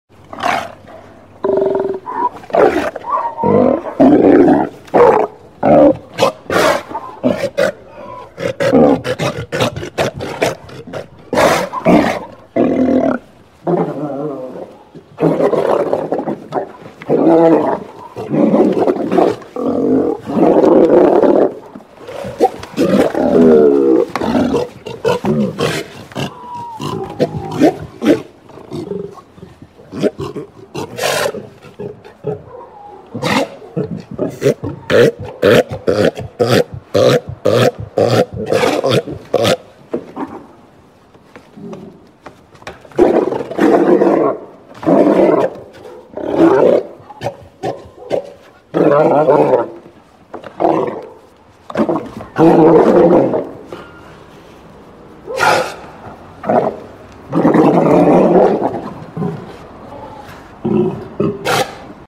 Suara Singa Laut
Kategori: Suara binatang liar
Suara teriakan yang nyaring dan penuh energi ini bisa digunakan untuk berbagai keperluan, mulai dari proyek kreatif, video, hingga efek suara yang menarik.
suara-singa-laut-id-www_tiengdong_com.mp3